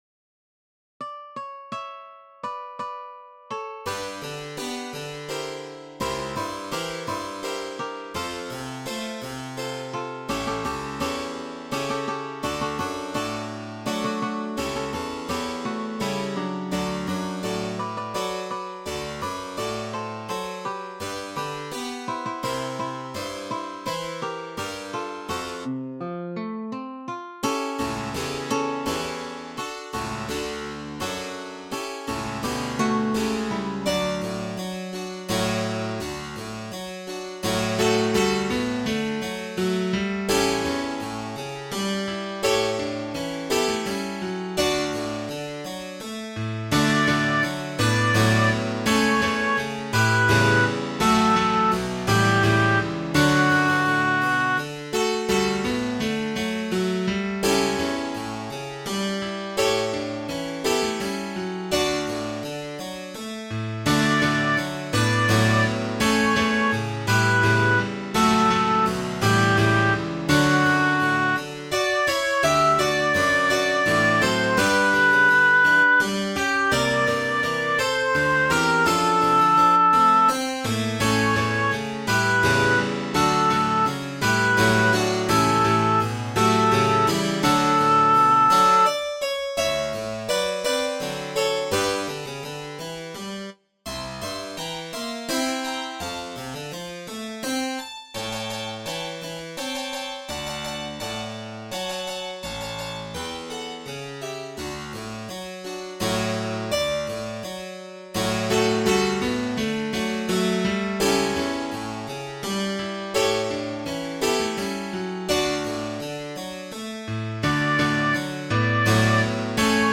navidad nuestra - 1 la anunciación-s.mp3